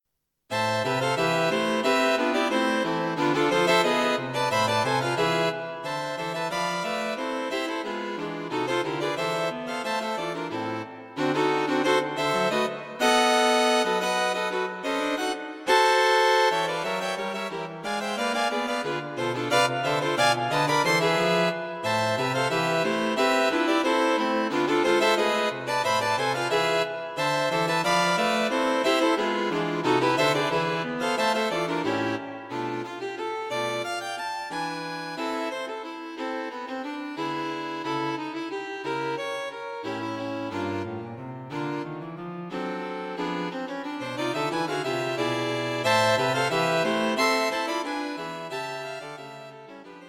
String Quartet for Concert performance